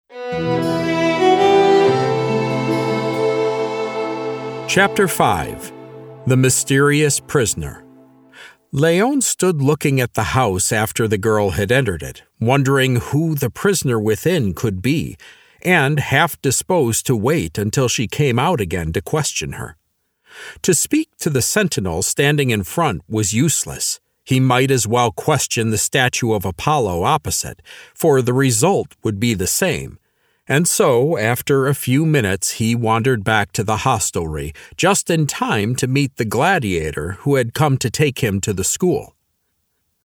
This is an audiobook, not a Lamplighter Theatre drama.
Glaucia-Audiobook-Sample.mp3